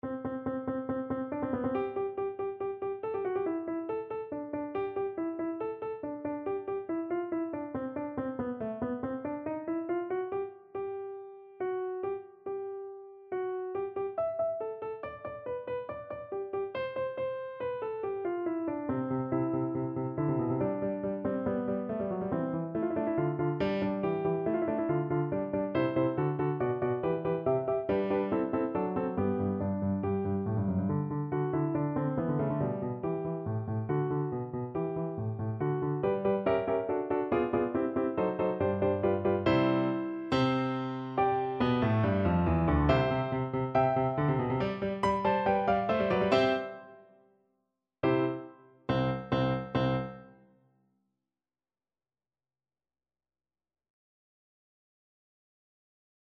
= 140 Allegro (View more music marked Allegro)
4/4 (View more 4/4 Music)
Classical (View more Classical French Horn Music)